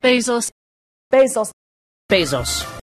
Brits, on the other hand, practically never use their GOAT vowel in the ending -os.
Unsurprisingly, some of them use this vowel in Bezos too: